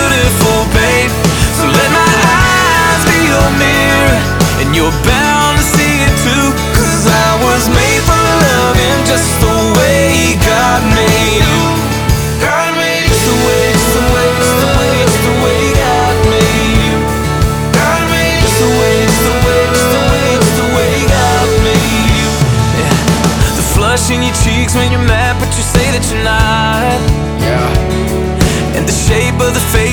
• Country
is an upbeat song with a catchy melody